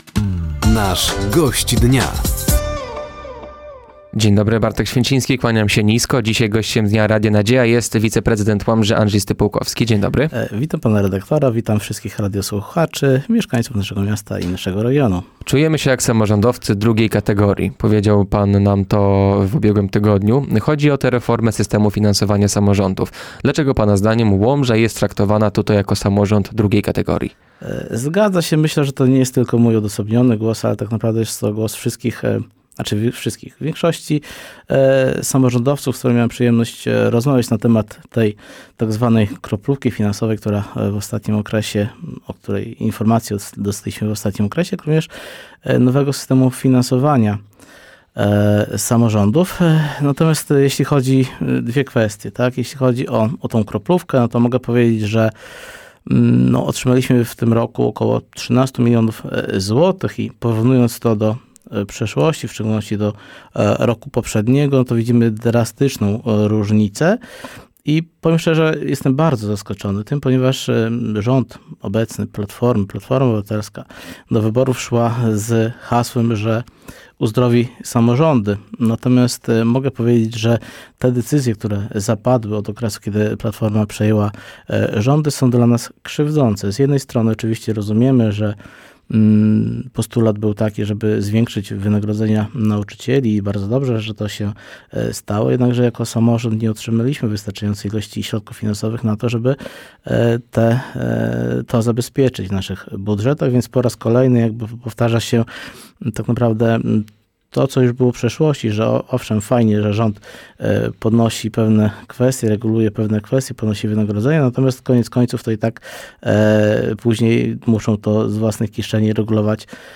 Gościem Dnia Radia Nadzieja był wiceprezydent Łomży Andrzej Stypułkowski. Tematem rozmowy było traktowanie łomżyńskiego samorządu przez rząd, podwyższenie podatków od nieruchomości oraz czy zastępca prezydenta chce być szefem PiS w Łomży.